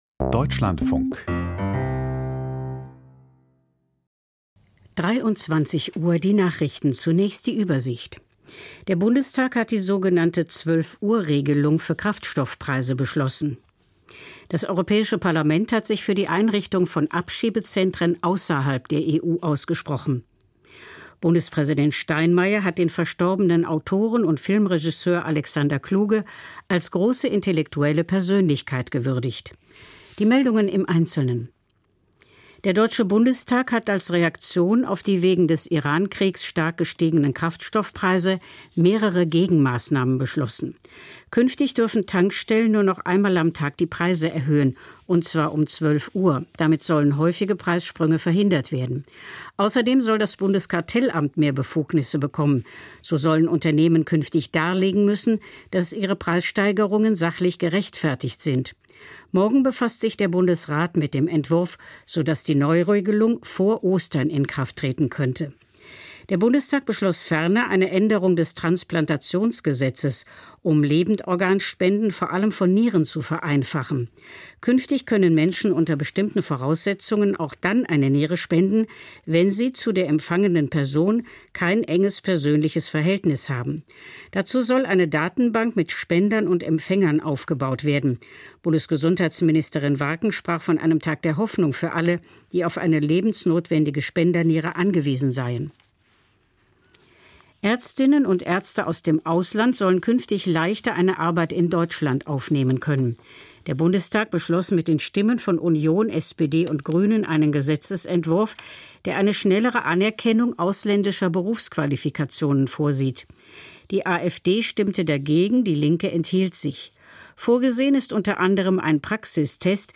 Die Nachrichten vom 26.03.2026, 23:00 Uhr